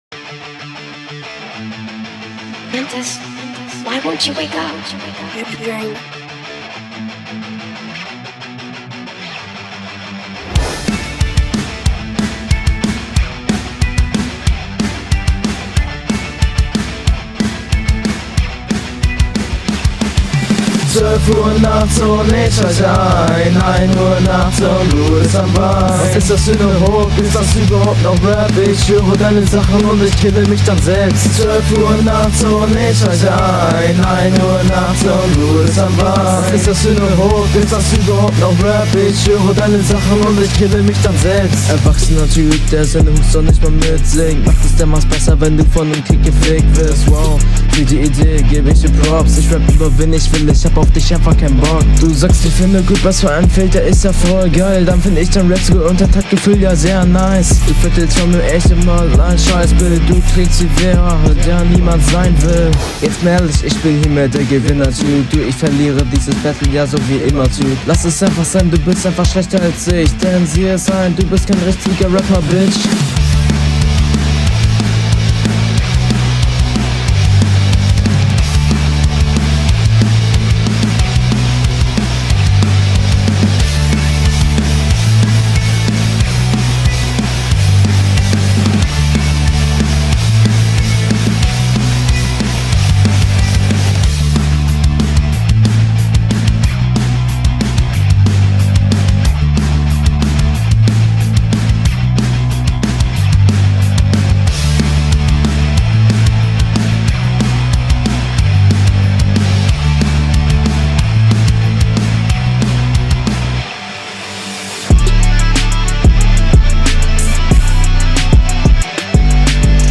Diese Hook hört sich besser an wahrscheinlich weil es mehrstimmig aufgenommen wurde und autotune drauf …
du hast die hook ein bisschen besser gesungen, aber auch nicht überkrass. in den parts …
hook ist besser. stimmeinsatz angehmer als beim gegner. teilweise offbeat.